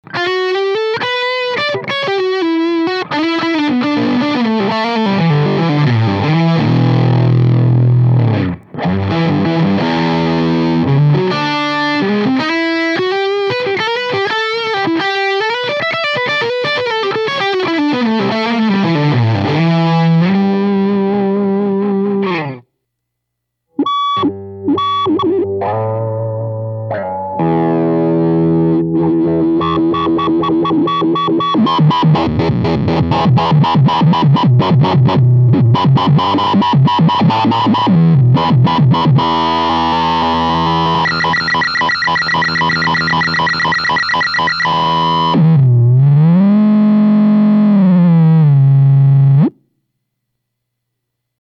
Â Both have recorded some excellent sound files that display the awesome sound of the Wave Cannon.
The tones he dialed up were Fuzz-Face influenced grind, but with superb touch sensitivity and responsive to changes in his attack. This demo that he recorded with a very nice Suhr guitar and amplifier is obviously not his first rodeo with high-gain tones, and we recommend checking it out. Â At the :23 mark, he can’t resist the “Havoc” switch either.